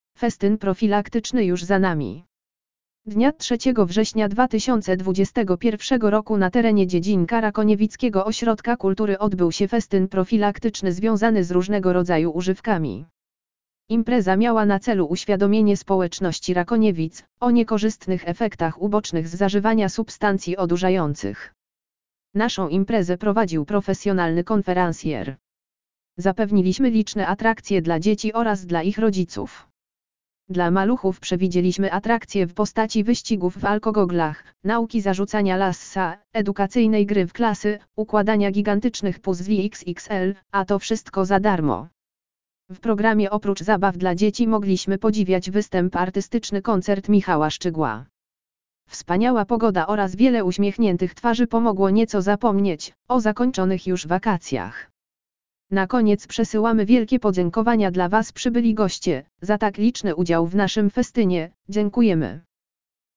lektor_audio_festyn_profilaktyczny_juz_za_nami_!.mp3